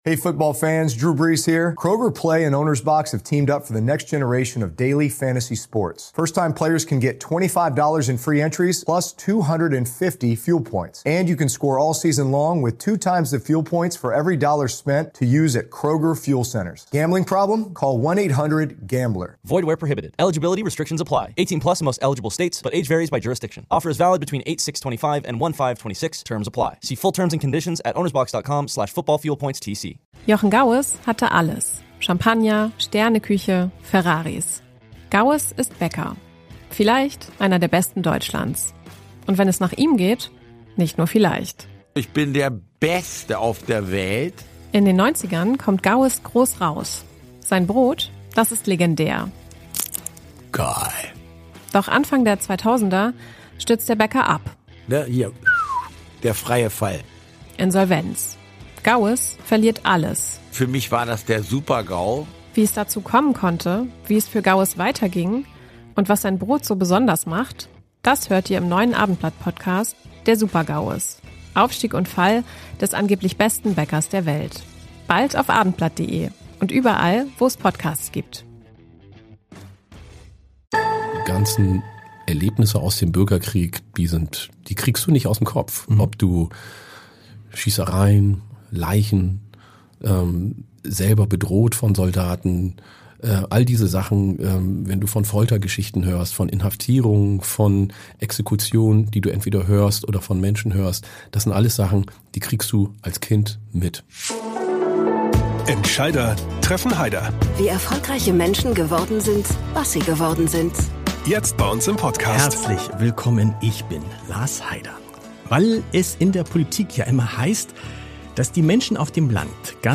Dibaba spricht über seinen ungewöhnlichen Werdegang, seine Liebe zu Plattdeutsch, wie er mit Rassismus umgeht und was das Leben auf dem Land für ihn bedeutet.